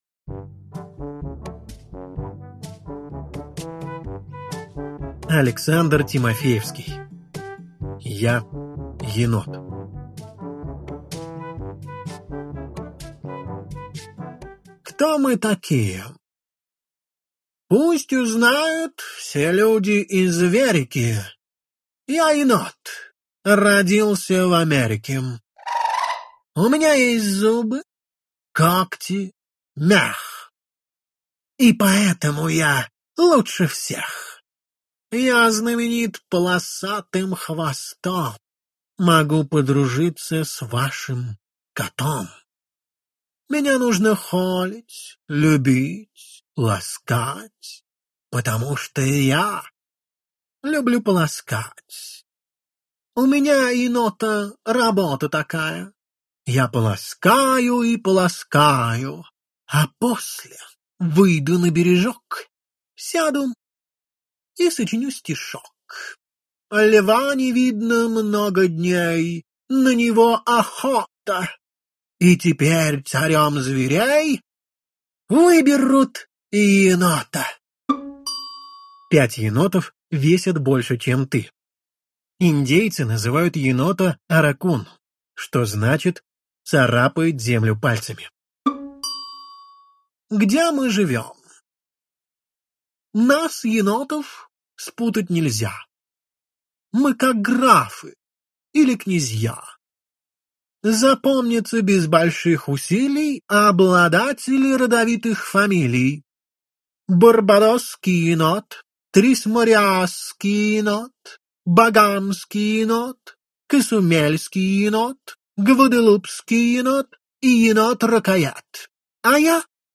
Аудиокнига Занимательная зоология. Сборник 7 в 1 | Библиотека аудиокниг